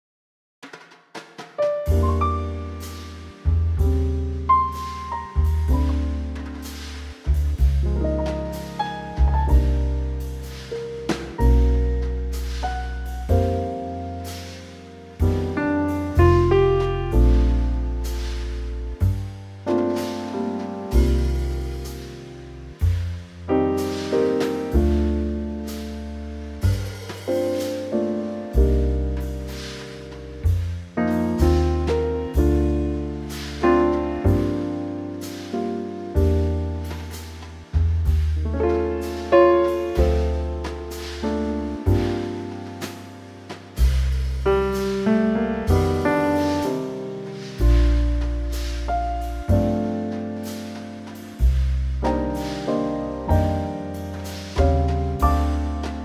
key - Bb - vocal range - Bb to D
Beautiful ballad
Here's a really gorgeous Trio arrangement.